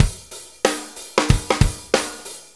Shuffle Loop 28-06.wav